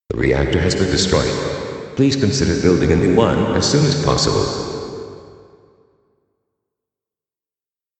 I just found a pretty nice text-to-speech that has a really great voice that sounds perfect for broadcast-like recording :smiley:
I changed the voice for one of them to see what it would sound like with a deeper voice saying it and another one sounded a little glitchy, but I liked the way it sounded :stuck_out_tongue: (It sounded like the voice was coming from the reactor itself and that the glitch was coming from the hits on the reactor.)